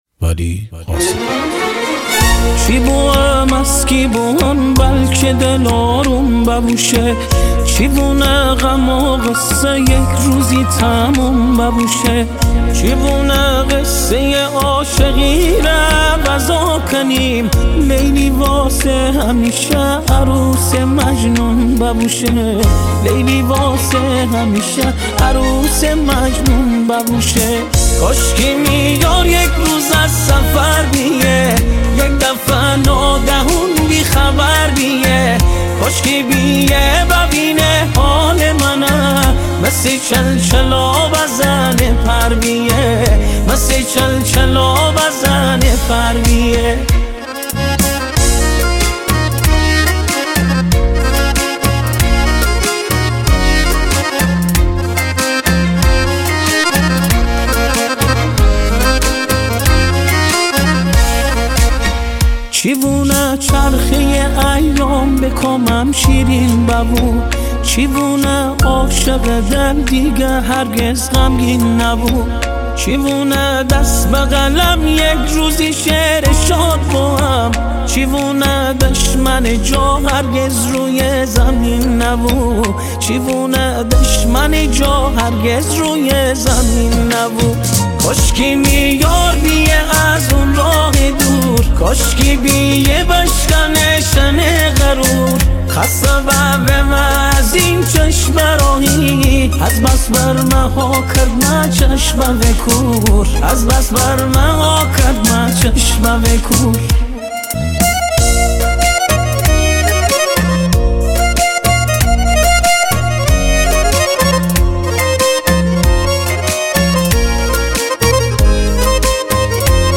آهنگ گیلانی
آهنگ های شاد شمالی